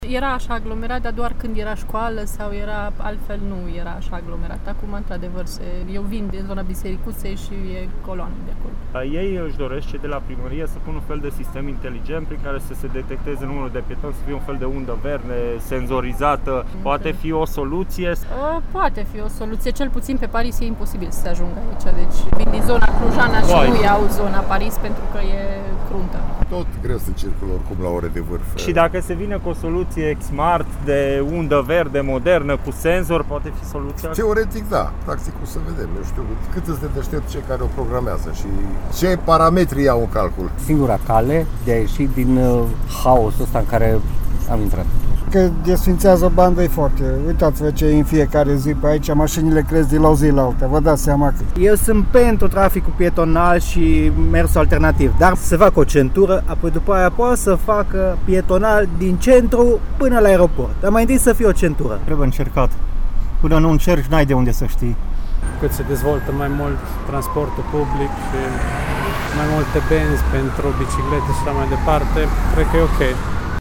Am întrebat atât conducători auto cât și pietoni cum privesc renunțarea la o bandă de circulație după încheierea proiectului și în ce măsură soluțiile smart pot decongestiona traficul: